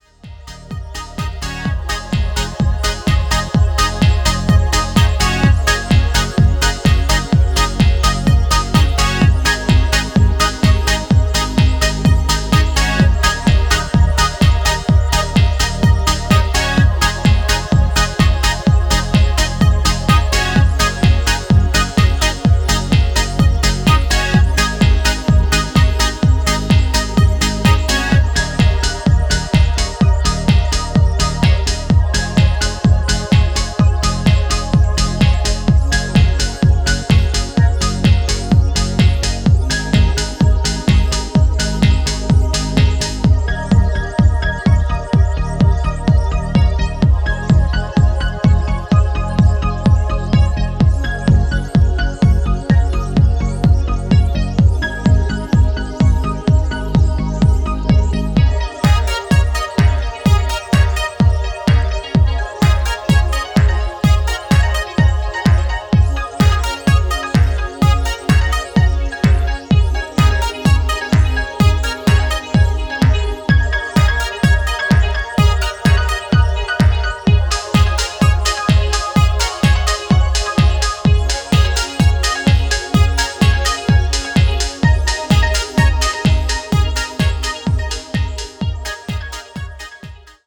トランシーでプログレッシヴなハウスを程よくミニマルに繰り広げていった、派手さやエグ味は控えめな仕上がりとなっています。